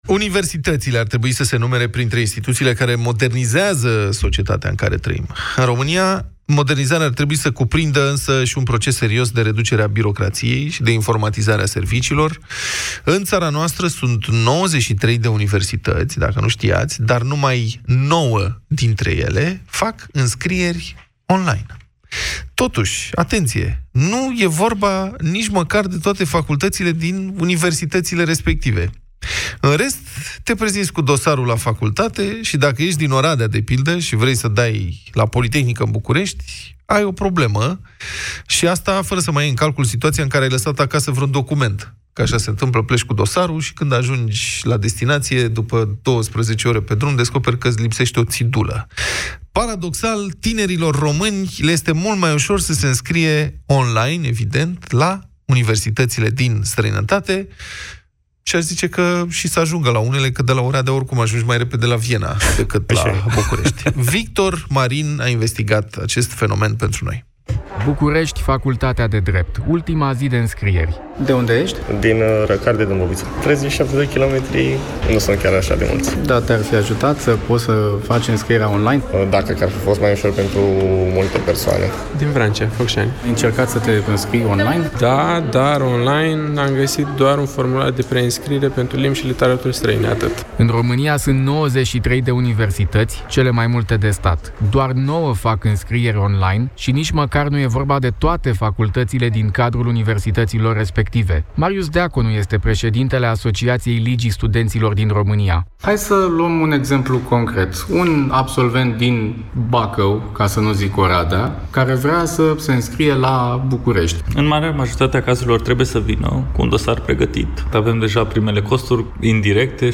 Reportaj: Absolvenților de liceu din România le este mai ușor să se înscrie la universitățile din străinătate
reportaj-europa-fm-inscriere-facultate.mp3